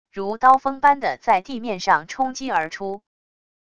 如刀锋般的在地面上冲击而出wav音频